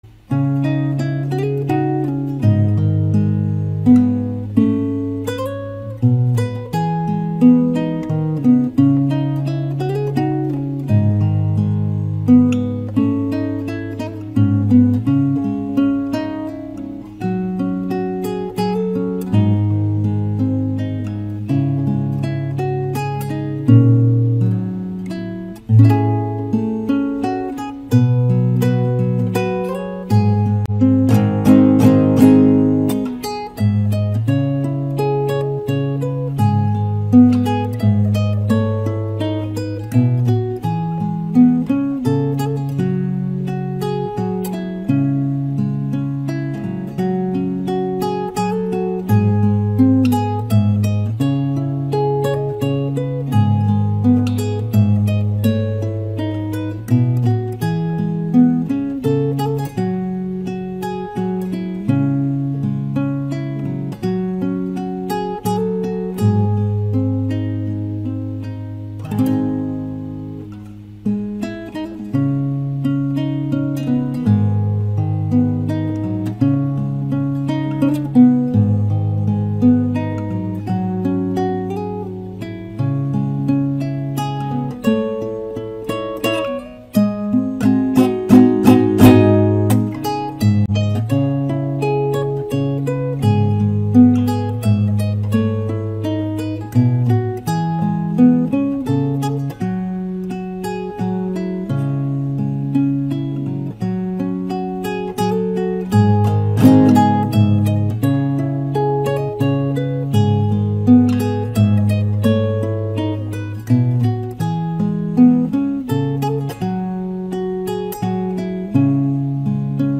giai điệu mộc mạc và da diết.